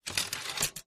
3 /4" Video tape deck tape loads and plays. Tape Loading Transport Engage Eject Tape